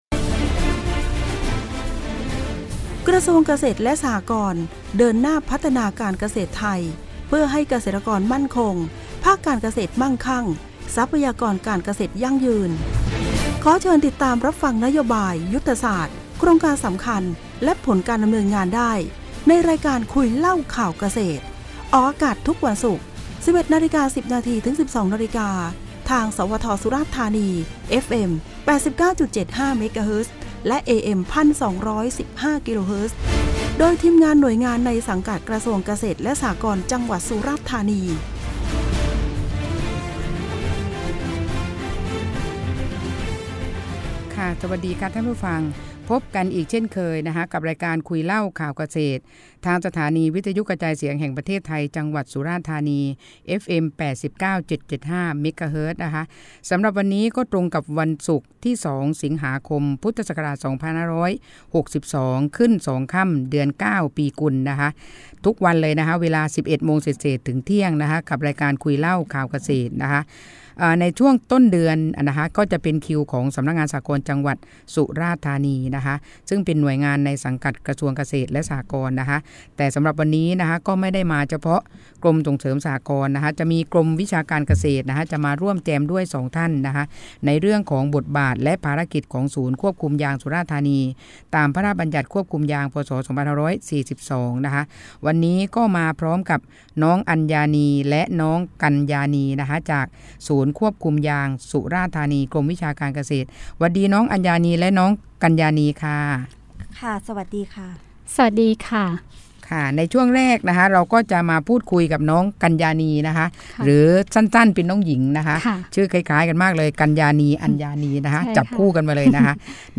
รายการวิทยุ “คุยเล่าข่าวเกษตร” ทางสวท.สฎ. 89.5 Mhz ออกอากาศในวันศุกร์ที่ 2 สิงหาคม 2562